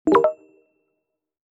Chat_Received.1667bc8453ca46364c16.mp3